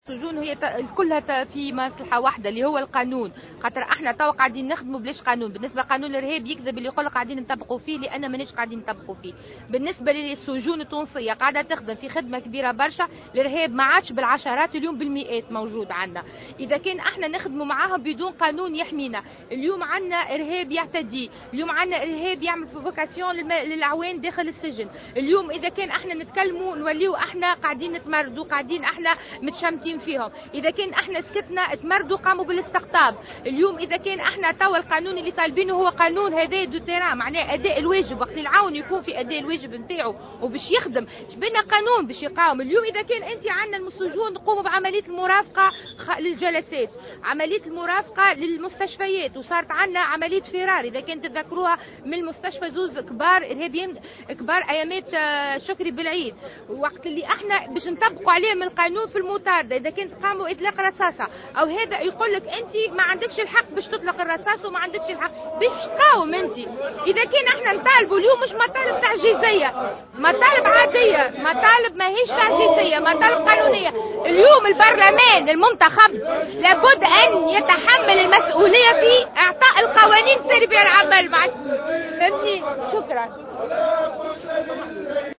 وقفة احتجاجية لنقابة السجون امام مجلس النواب